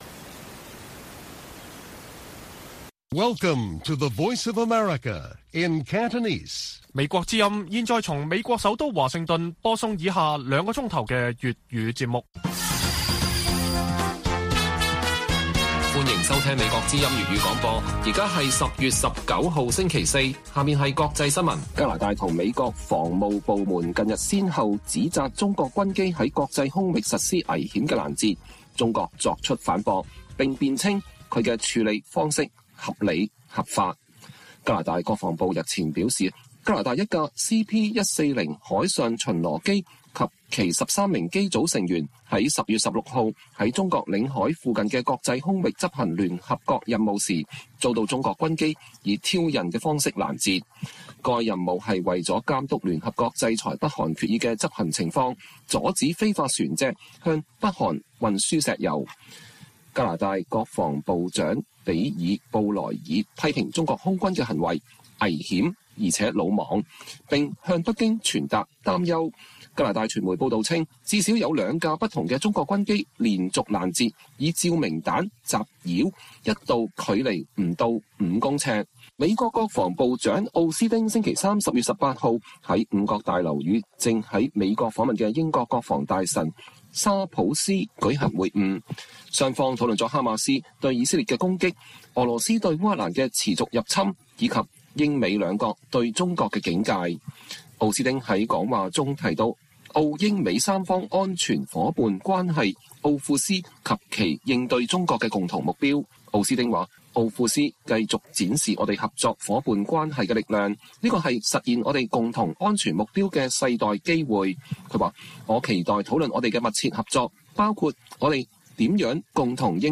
粵語新聞 晚上9-10點 : 香港區議會選舉提名期展開 有民主派區議員指新選制民主倒退不尋求連任